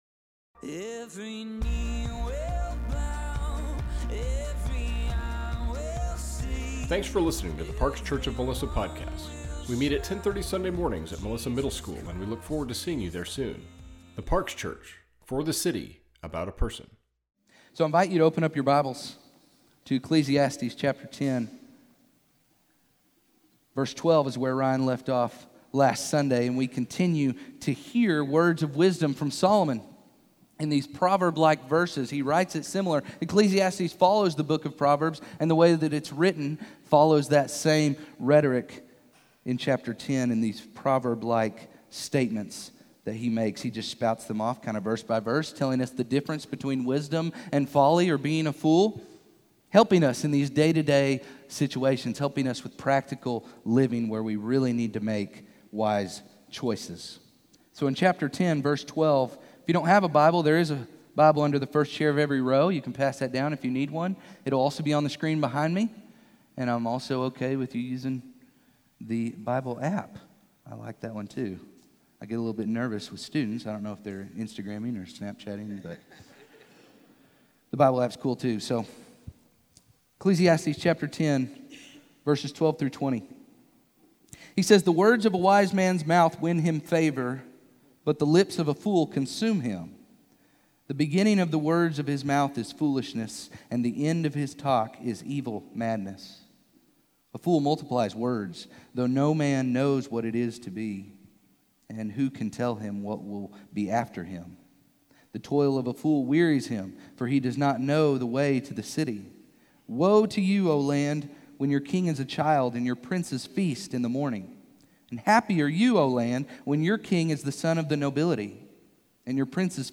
*sadly, this is only a partial recording*